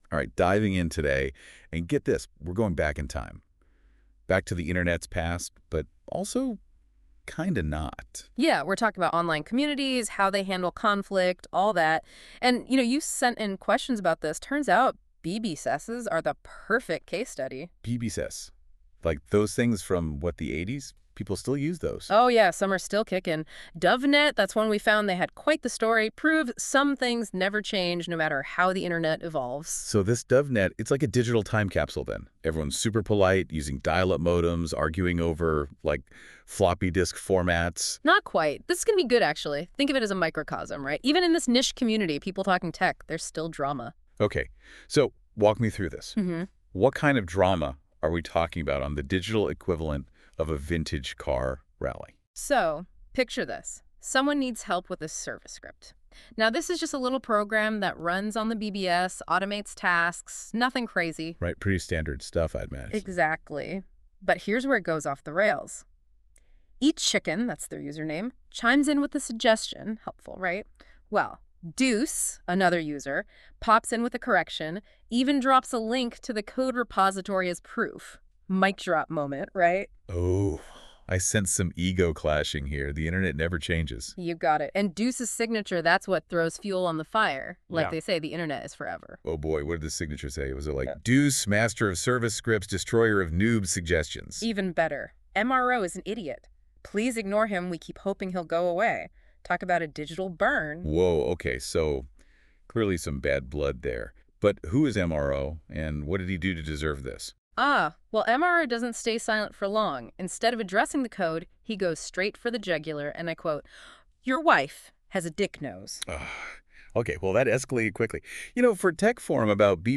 Yes, by Google's NotebookML.
Have any of you guys played around with Google's "NotebookLM", specifically the "Generate a Conversation" function that simulates two podcasters discussing shit?
DoveNet = pronounced Duh Veh Net.
yeah it's disturbing stuff. it's 1000x more polished than most podcasts that i've heard.
There's a bunch of pronunciation issues and my name is said wrong, so that